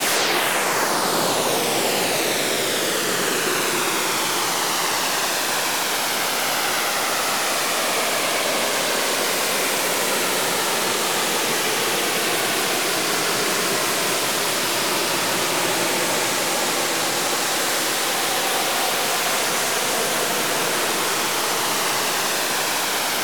ULTRASWEEP.wav